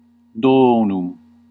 Ääntäminen
Ääntäminen : IPA : /ˈɡɪft/ US : IPA : [ˈɡɪft]